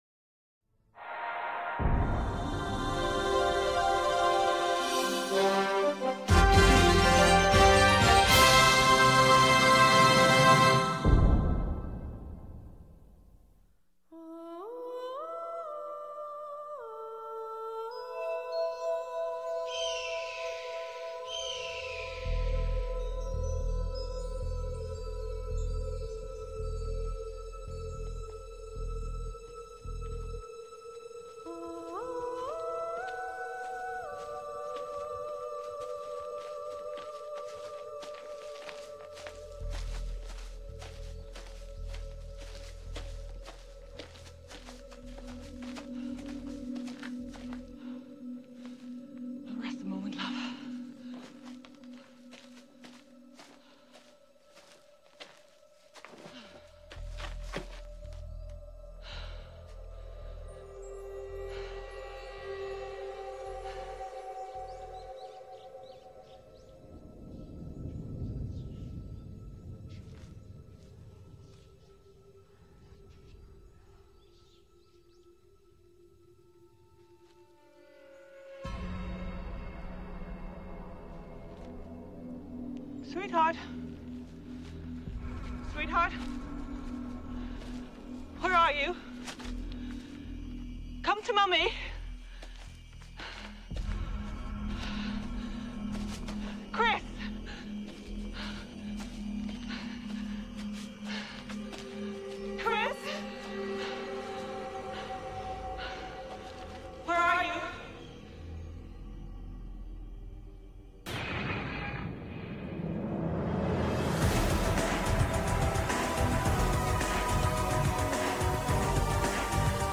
It's also filled to the brim with music samples, as was common with films around this era.